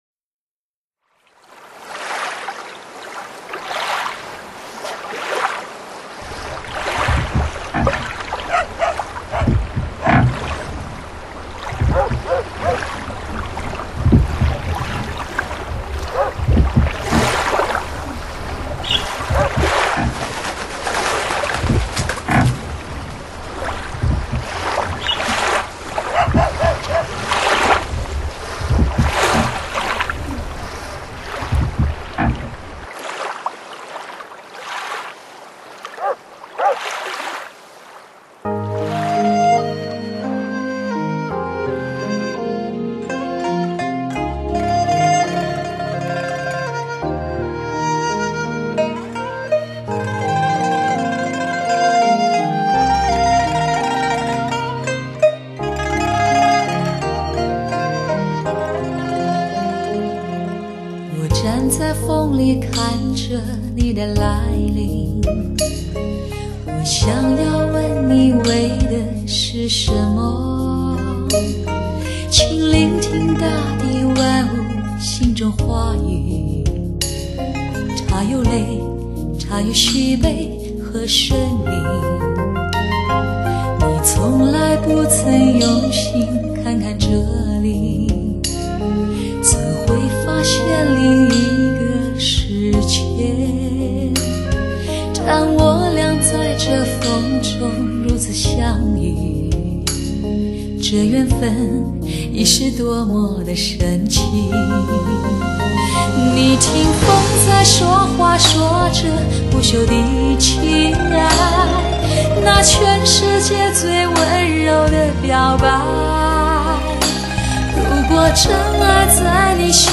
采用最新美国DTS-ES6.1顶级编码器,带来超乎想象震撼性6.1环绕体验。